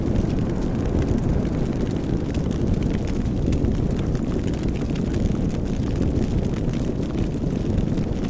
MolotovFireLoop.wav